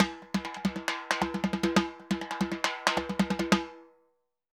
Timba_Merengue 136_2.wav